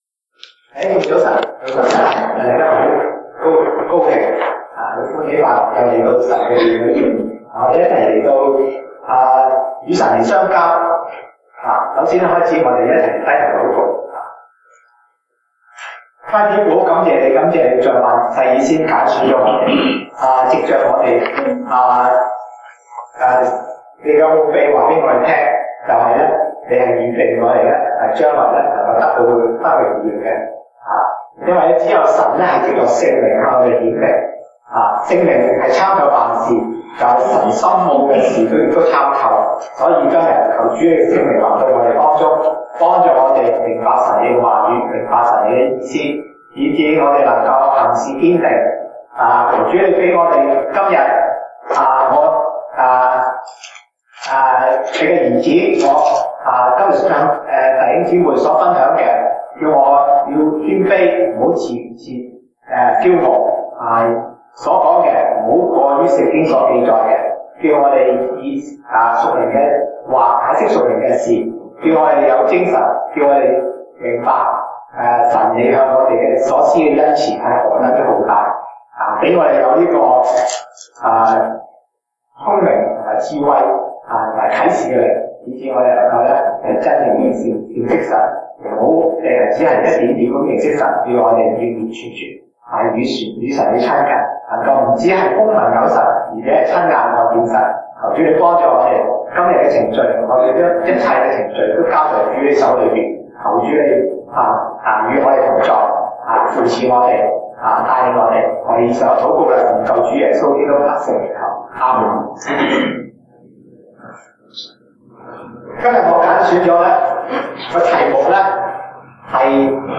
東北堂證道 (粵語) North Side: 神如何拯救衪的兒女
Passage: 啟示錄 Revelation 9:1-21 Service Type: 東北堂證道 (粵語) North Side (First Church)